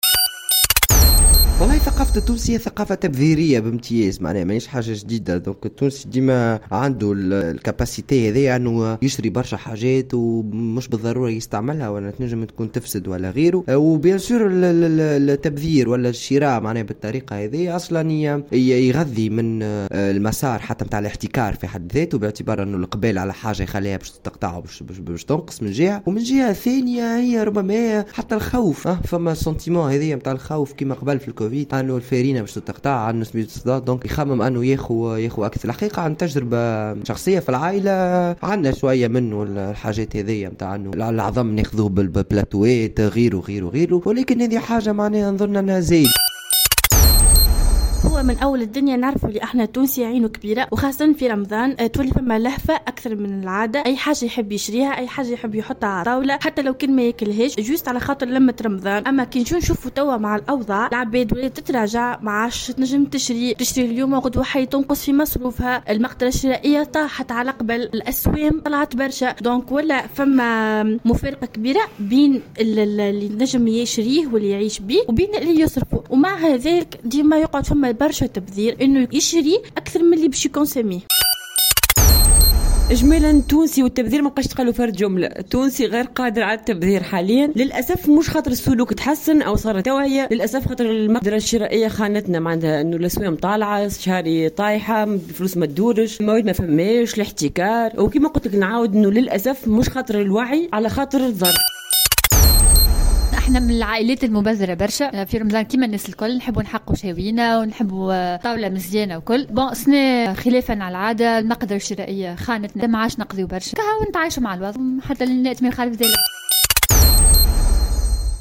قال عدد من المواطنين إن ثقافة التونسي هي "ثقافة تبذيرية بامتياز"، خاصة خلال شهر رمضان.